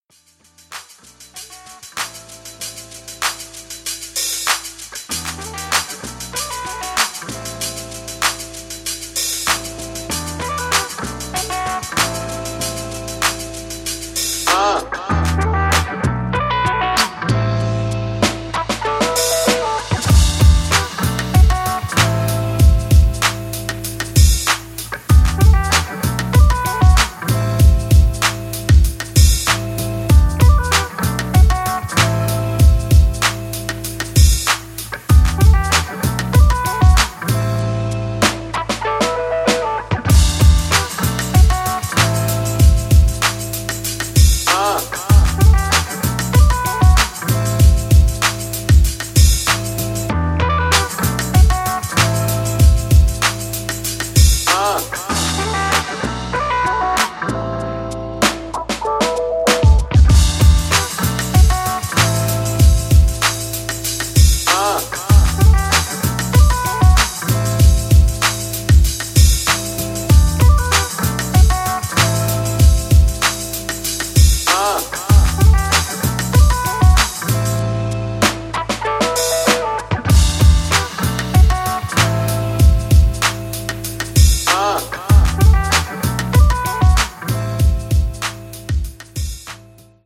ジャンル(スタイル) NU DISCO / DISCO HOUSE / DEEP HOUSE